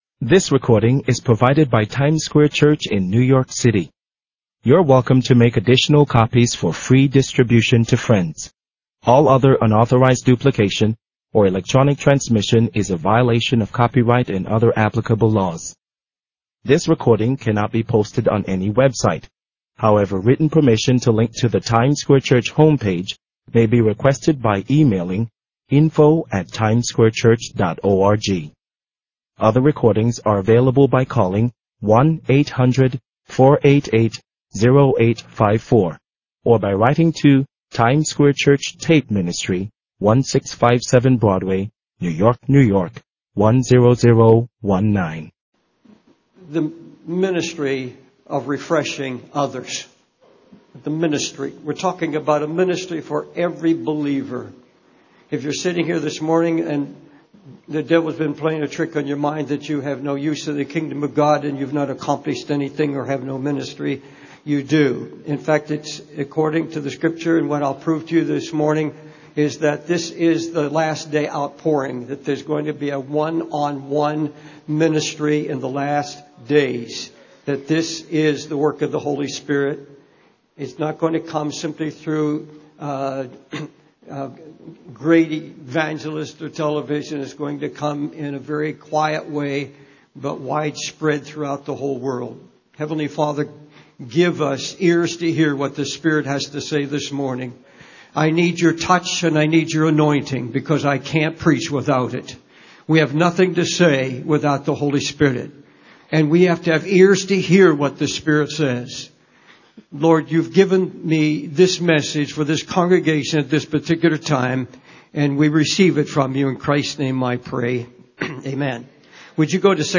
The Ministry of Refreshing Others by David Wilkerson | SermonIndex
This sermon emphasizes the ministry of refreshing others, highlighting the importance of believers having a ministry and being used by God to bring comfort and refreshment to those in need. It discusses the last days outpouring of the Holy Spirit, focusing on one-on-one ministry and the need for believers to be vessels of God's refreshing spirit.